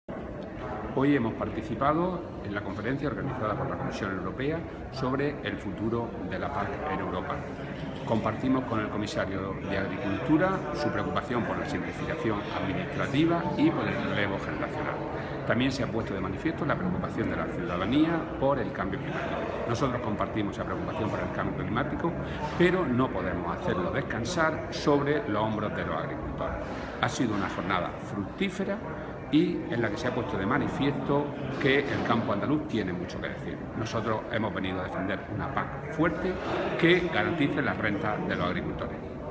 Declaraciones de Rodrigo Sánchez sobre la PAC